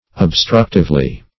Obstructive \Ob*struct"ive\, a. [Cf. F. obstrictif.]